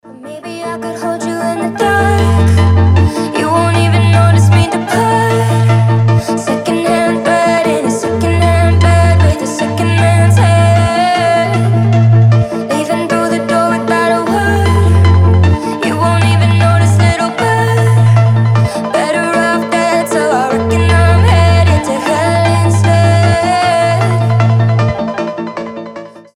• Качество: 320, Stereo
красивый женский голос
alternative
experimental